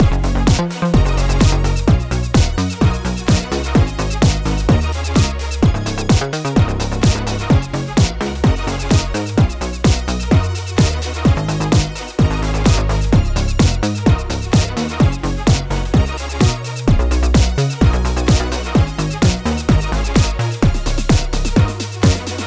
Music - Song Key
Gb Major
Classic Warm
Music - Epic Strings
Music - Percussion
Music - Toy